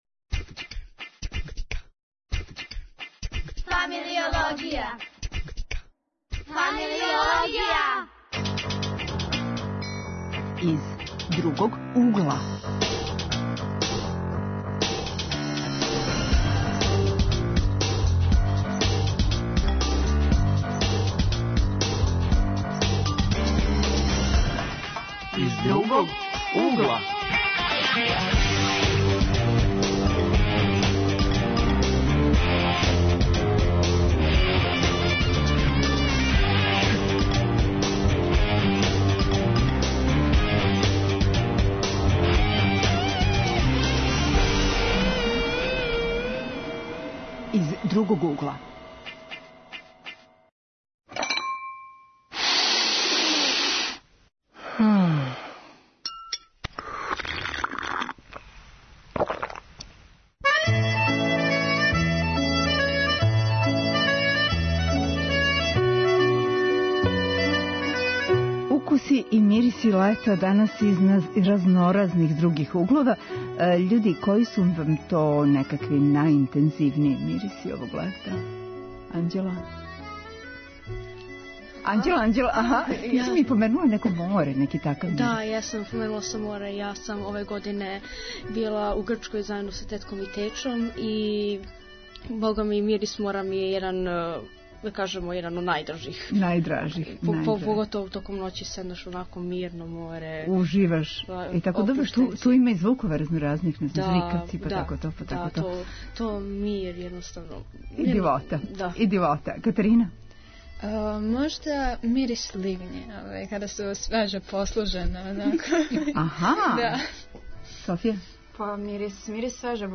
Гости: млади полазници Радионице графичког дизајна инспирисани необичним укусима